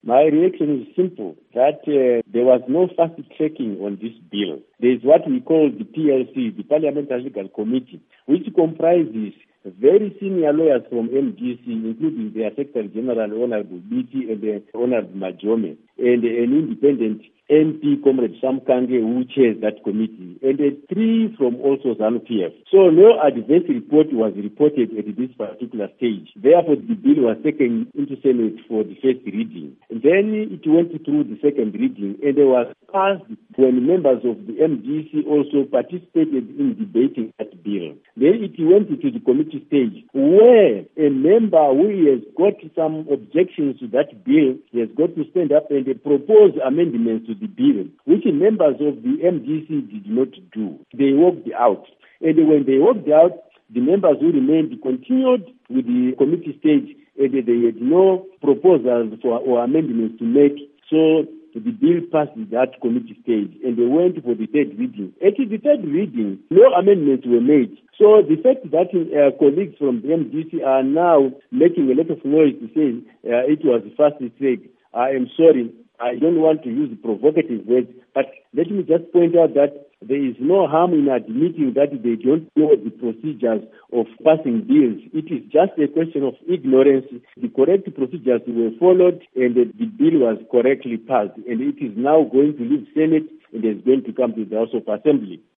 Interveiw With Joram Gumbo